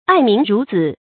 爱民如子 ài mín rú zǐ 成语解释 旧时称赞某些统治者爱护百姓，就象爱护自己的子女一样。